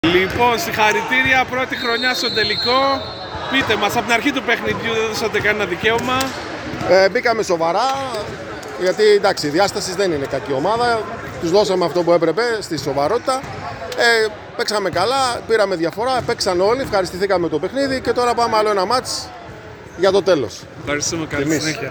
Δηλώσεις Πρωταγωνιστών: